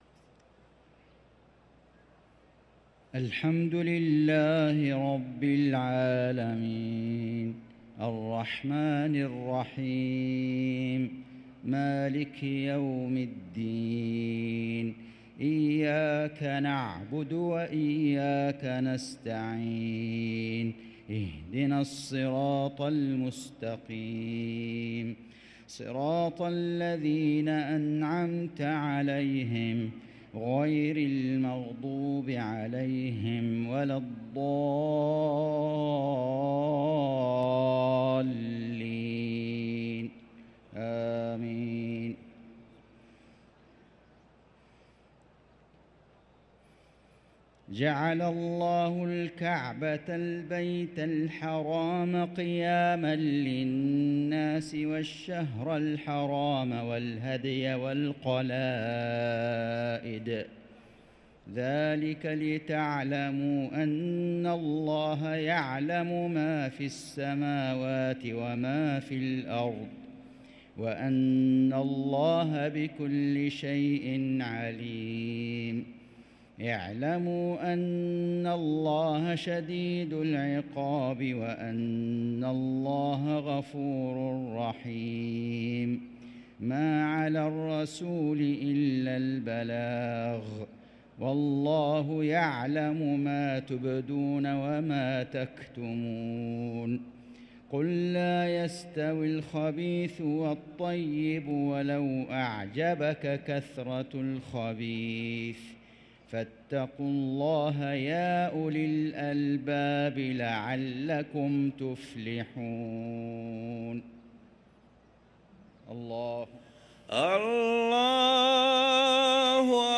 صلاة المغرب للقارئ فيصل غزاوي 3 ربيع الأول 1445 هـ
تِلَاوَات الْحَرَمَيْن .